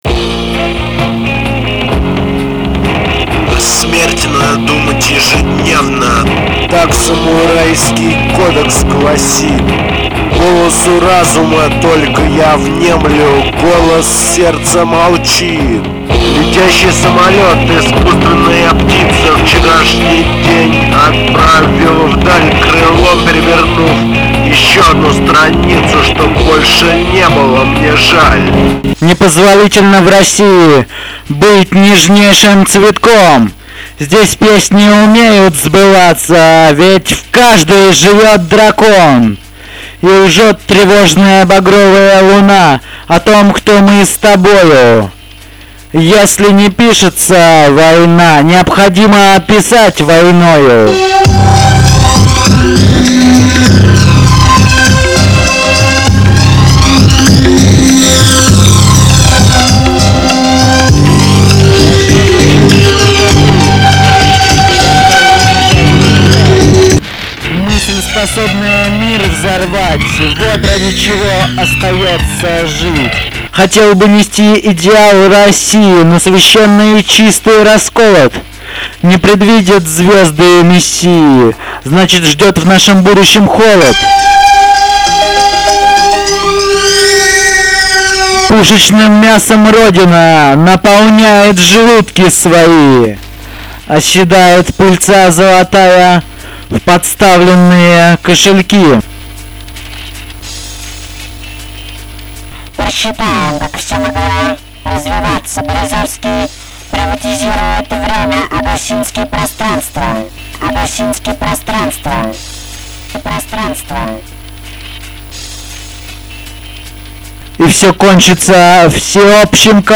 домашней студии